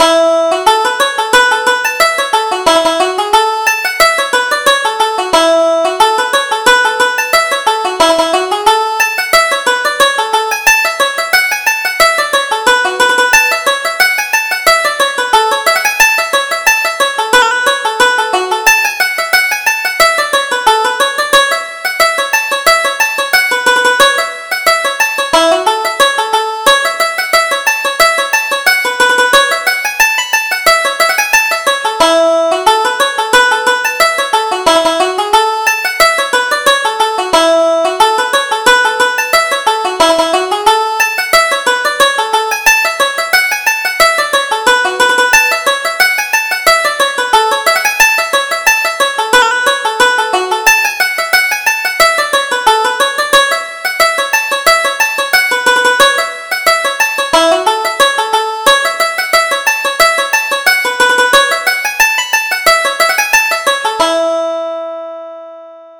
Reel: McFadden's Handsome Daughter